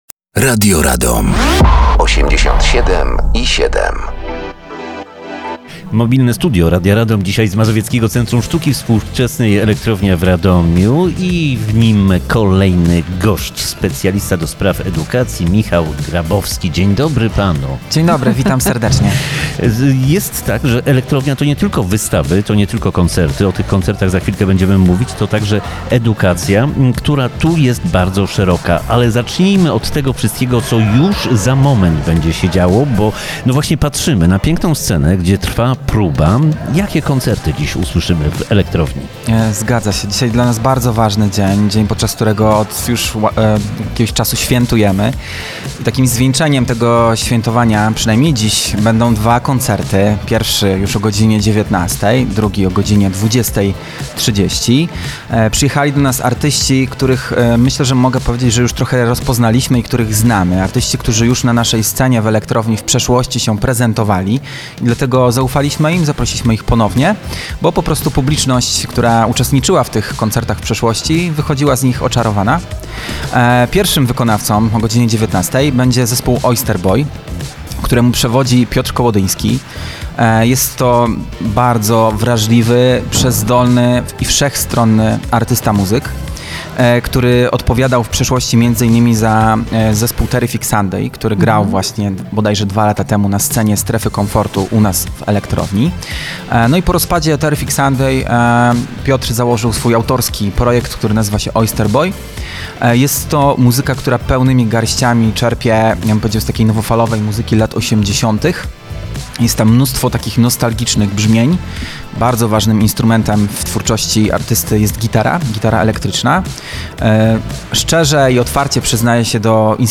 Mobilne Studio Radia Radom dzisiaj w Mazowieckim Centrum Sztuki Współczesnej Elektrownia w Radomiu.